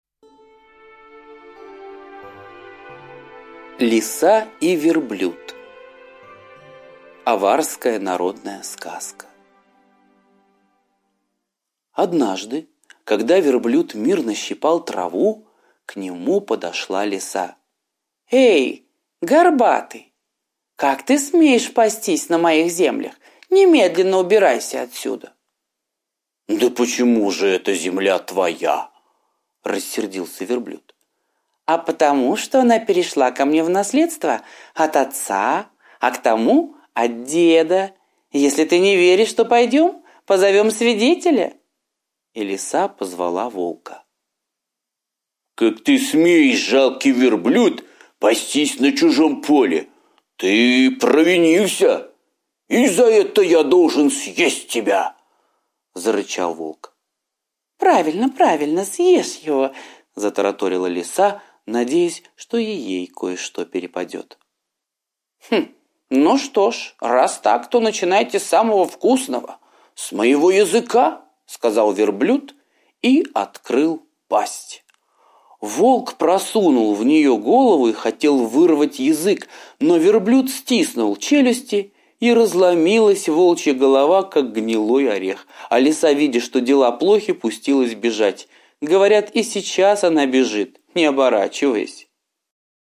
Лиса и Верблюд – азиатская аудиосказка